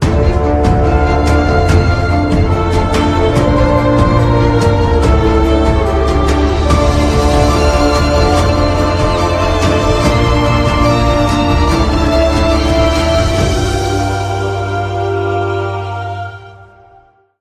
Category: Theme songs